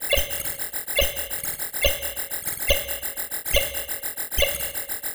COMPUTER_Sci-Fi_Processing_01_loop_mono.wav